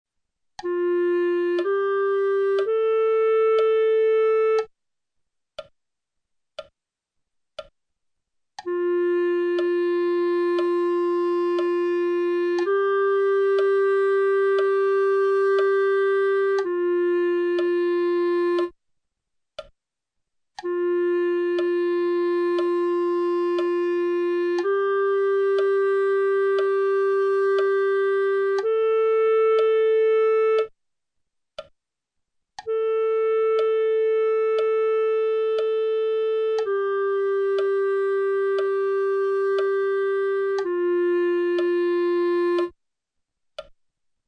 Prima dei dettati veri e propri, sentirai le note che verranno proposte, seguite da una battuta vuota scandita nella divisione dal metronomo.
Note: Fa - Sol - La
Tempo: 4/4